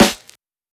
Snare (9).wav